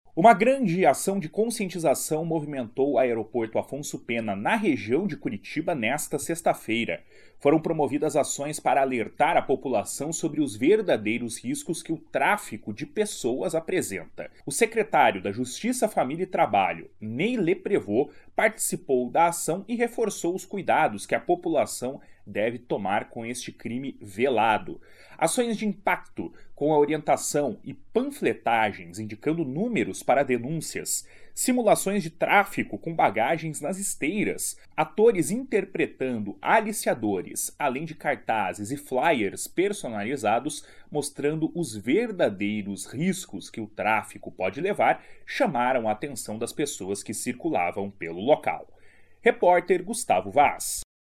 Foram promovidas ações para alertar a população sobre os verdadeiros riscos que o tráfico de pessoas apresenta. O secretário da Justiça, Família e Trabalho, Ney Leprevost, participou da ação e reforçou os cuidados que a população deve tomar com este crime velado.